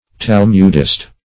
Search Result for " talmudist" : The Collaborative International Dictionary of English v.0.48: Talmudist \Tal"mud*ist\, n. [Cf. F. talmudiste.] One versed in the Talmud; one who adheres to the teachings of the Talmud.